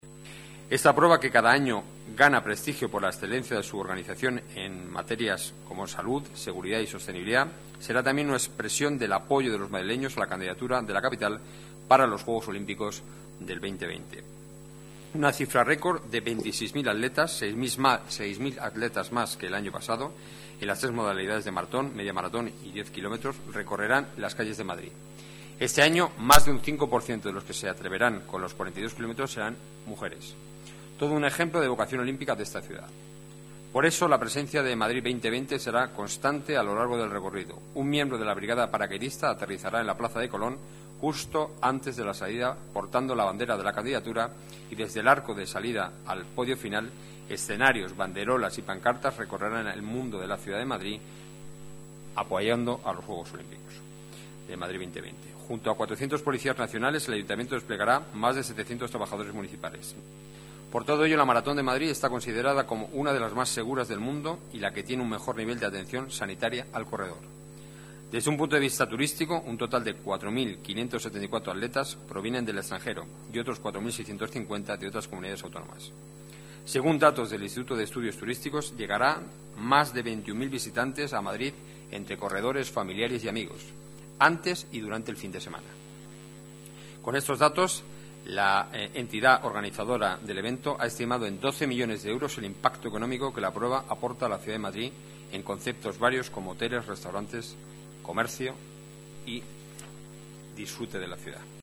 Nueva ventana:Enrique Núñez, portavoz gobierno municipal, Maratón de Madrid